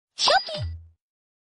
Notification File format